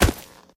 Divergent / mods / Footsies / gamedata / sounds / material / human / step / default2.ogg